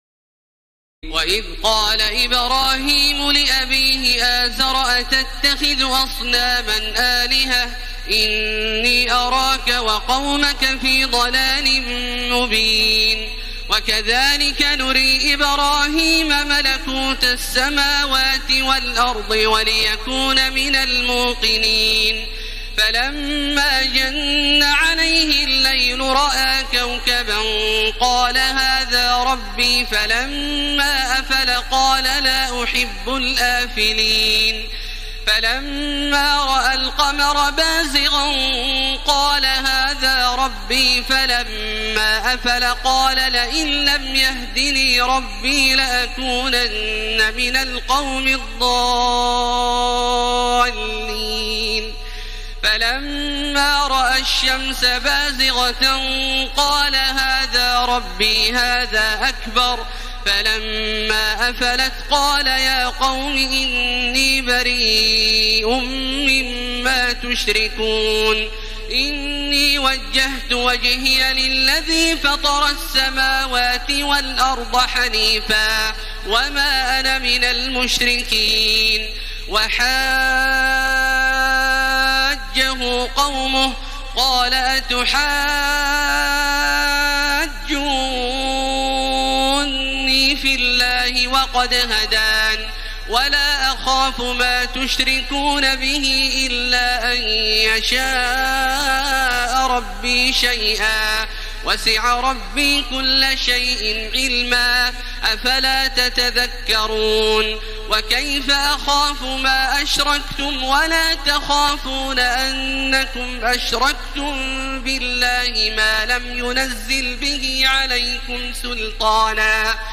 تراويح الليلة السابعة رمضان 1434هـ من سورة الأنعام (74-150) Taraweeh 7 st night Ramadan 1434H from Surah Al-An’aam > تراويح الحرم المكي عام 1434 🕋 > التراويح - تلاوات الحرمين